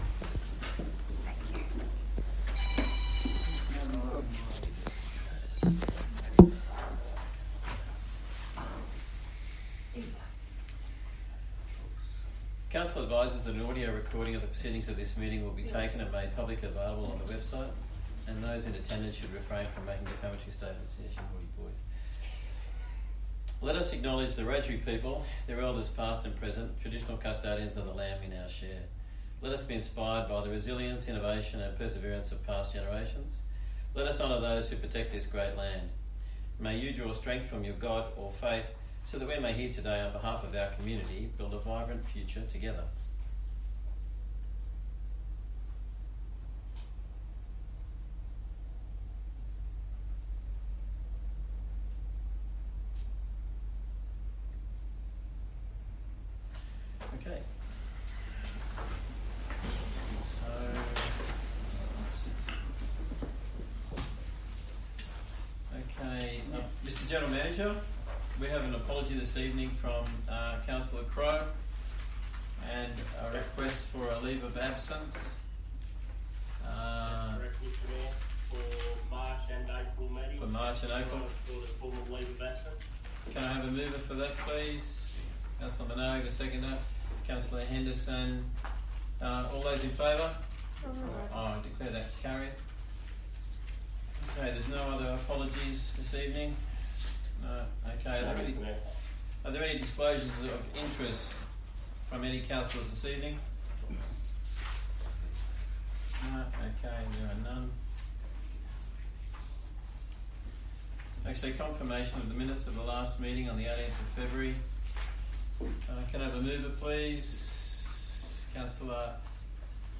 18 March 2025 Ordinary Council Meeting
Bland Shire Council Chambers, 6 Shire Street, West Wyalong, 2671 View Map